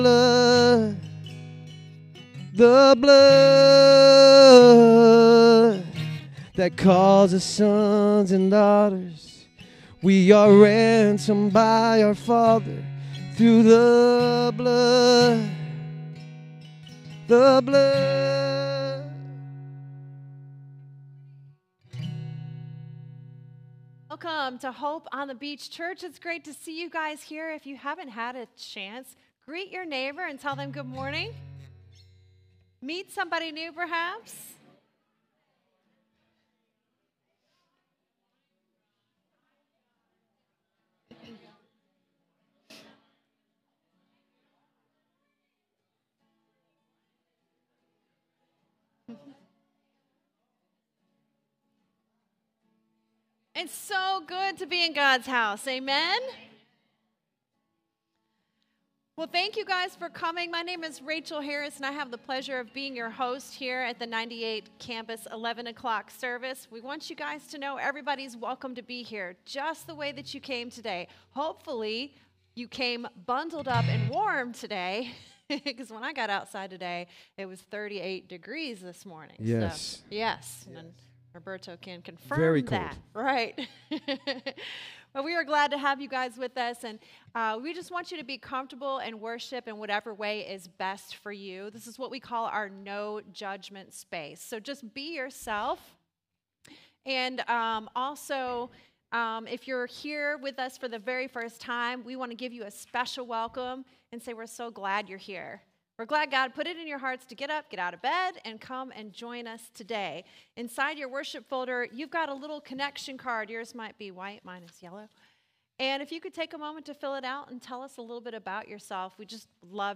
98 Campus | Hope on the Beach Church